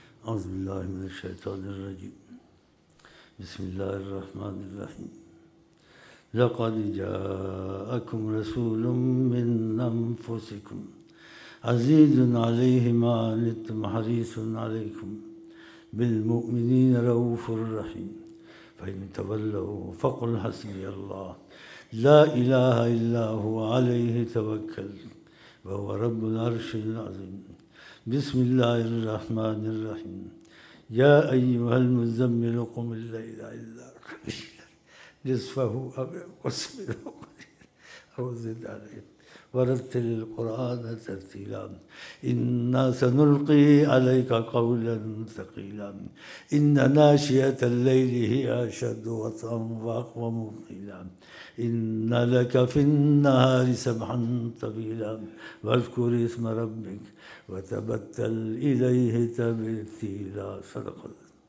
Talawat ( Laqad Ja akum )